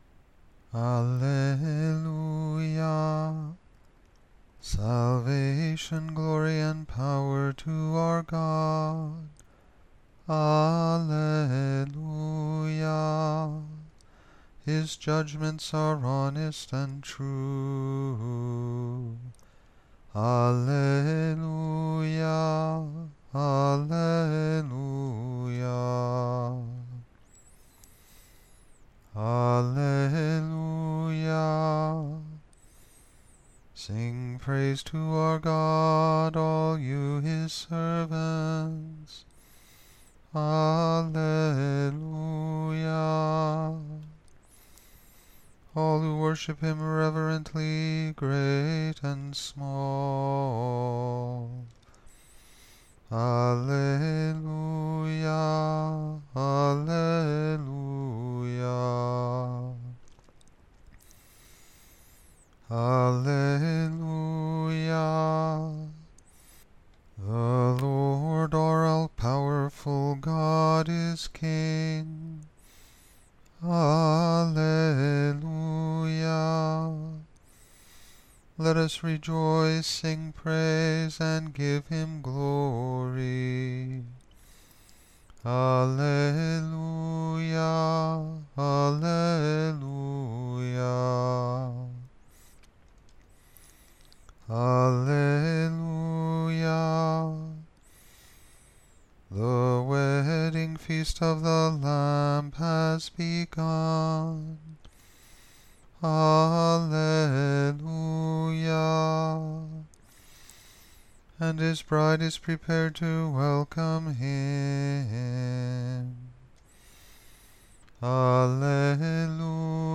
Caveat: this is not Gregorian Chant.